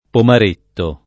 Pomaretto [ pomar % tto ] top. (Piem.)